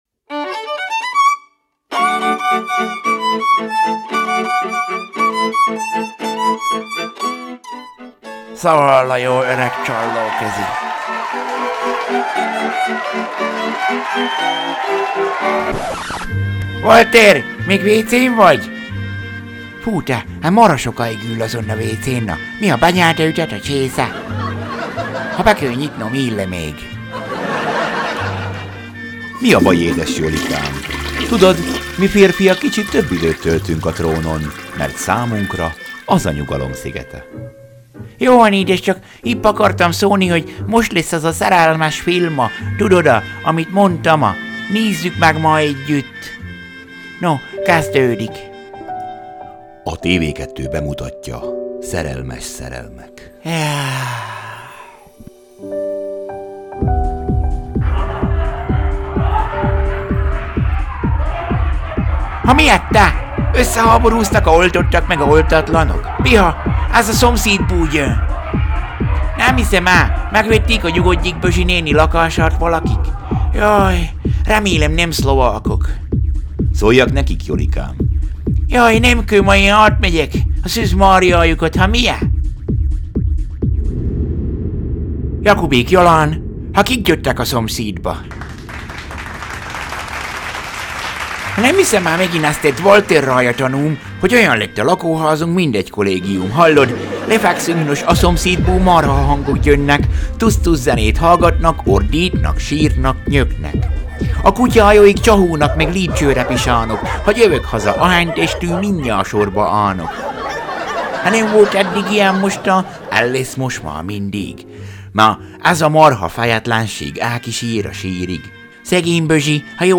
Szaval a jó öreg csallóközi
Pósfa zenekar - Ó, én édes komámasszony
Szomszédok főcímzene